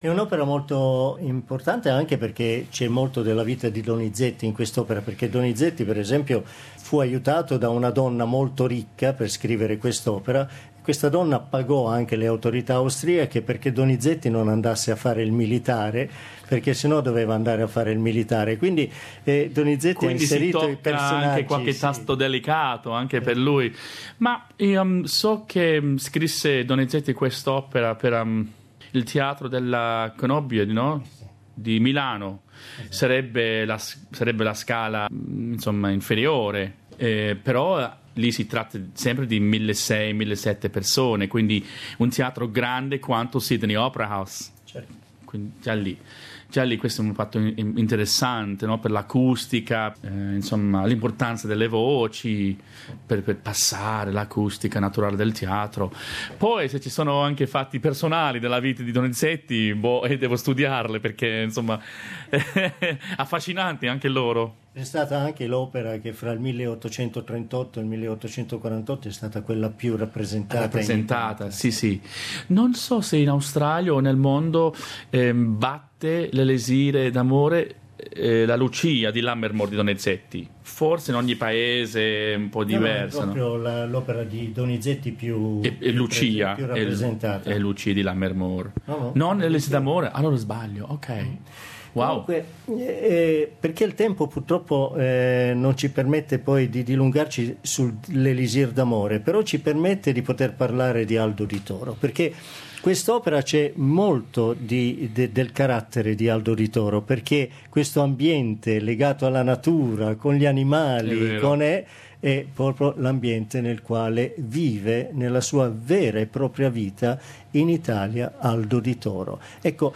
Seconda parte dell'intervista